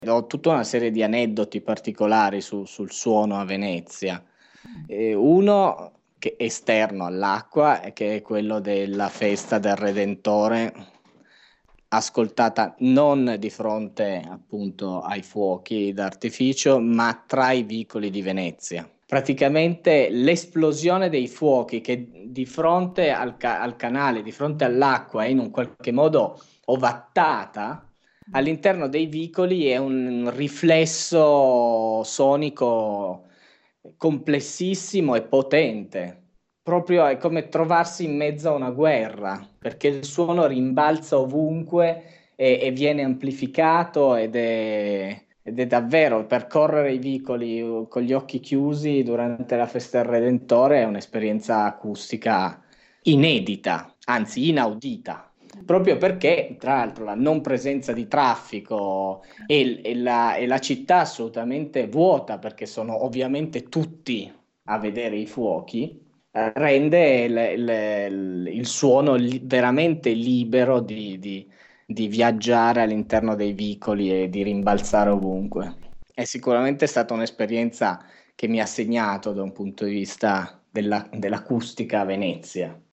L’esplosione dei fuochi, sul canale, è un esperienza pirotecnica normale mentre all’interno dei vicoli si crea un riflesso sonico complesso e potente, perchè il suono rimbalza ovunque e viene amplificato:  percorrere i vicoli con gli occhi chiusi, durante la Festa del Redentore, è un’esperienza acustica inedita, anzi inaudita. L’assenza di traffico e la città vuota, proprio perchè tutte le persone sono concentrate davanti al canale per osservare i fuochi d’artificio, rende il suono libero di viaggiare all’interno delle calli e di rimbalzare ovunque.
2-venezia-redentore.mp3